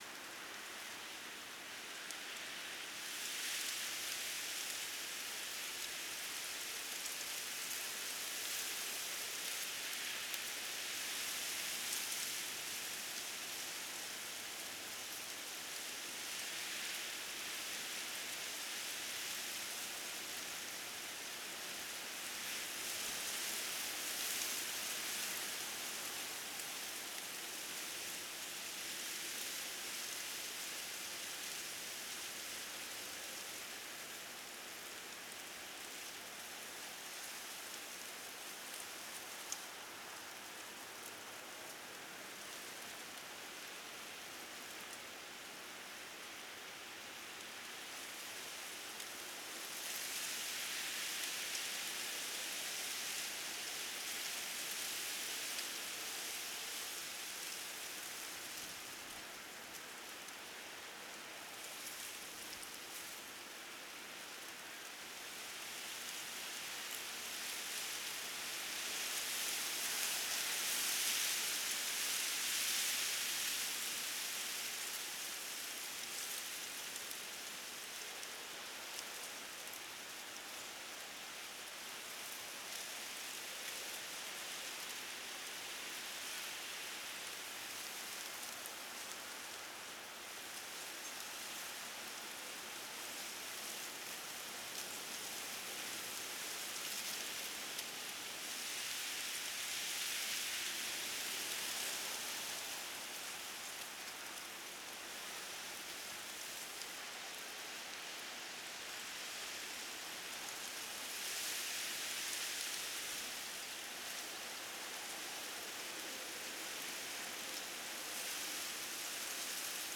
WIND_THRU_GRASS.L.wav